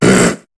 Cri de Spoink dans Pokémon HOME.